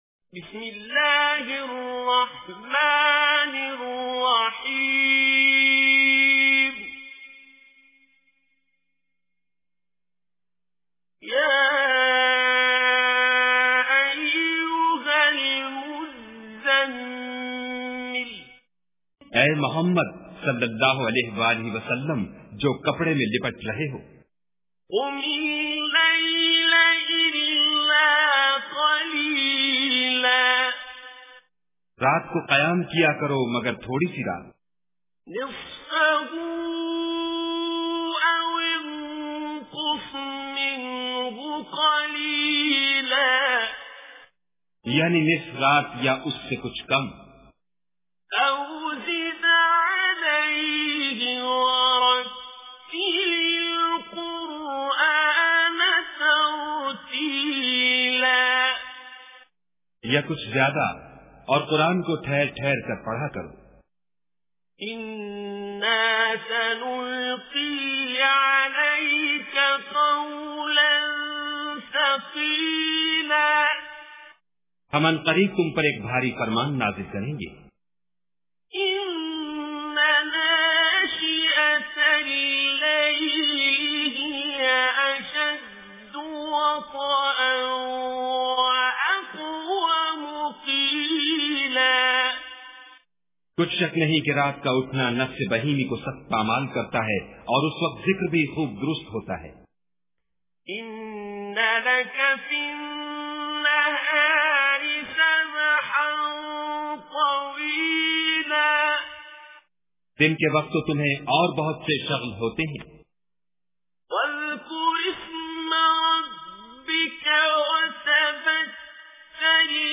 Surah Muzammil Recitation with Urdu Translation
Surah Muzammil 73 chapter of Holy Quran. Surah Muzammil, listen online recitation in Arabic.
surah-muzammil.mp3